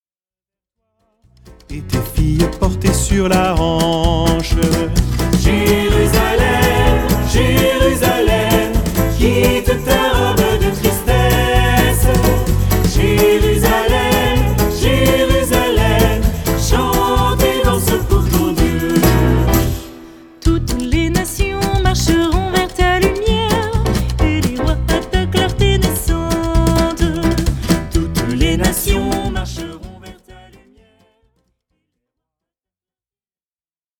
Célèbres chants de Louange